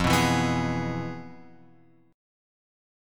F Minor Major 7th Flat 5th